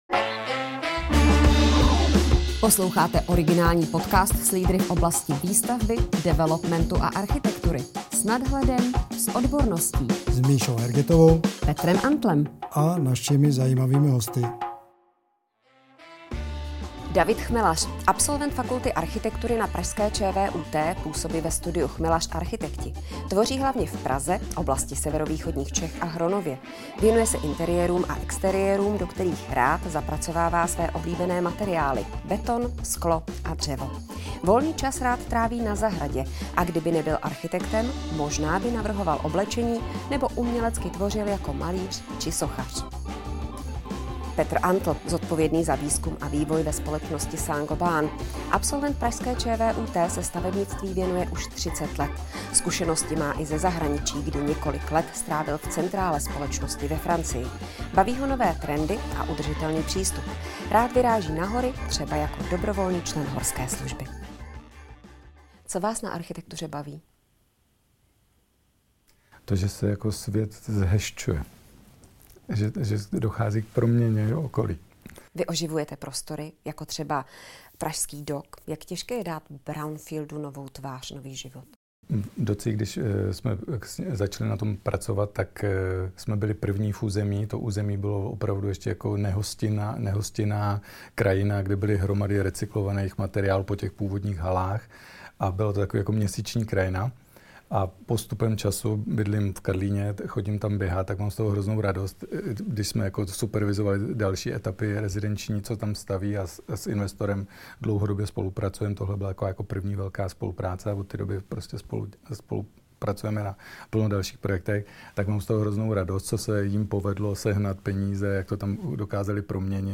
Jak vůbec vznikají nové materiály? Přichází impuls od výrobce, nebo od architekta? Nejen to se dozvíte v další diskuzi.